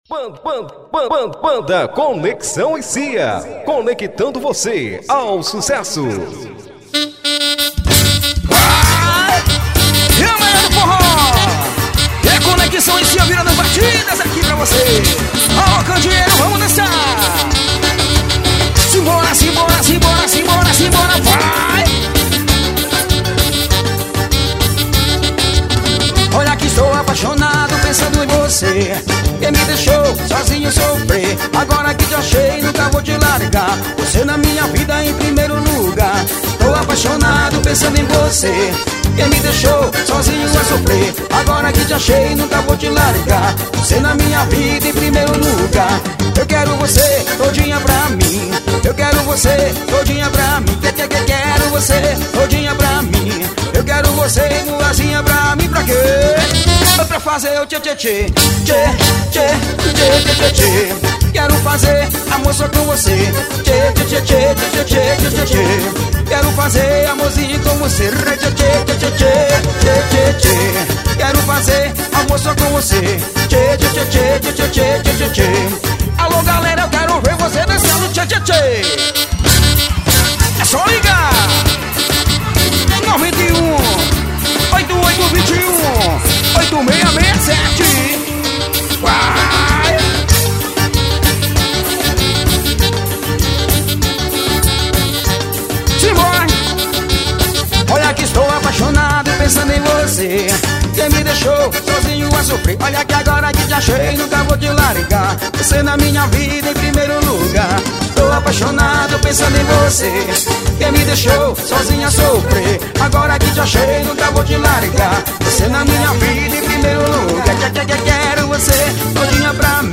Banda de Forró.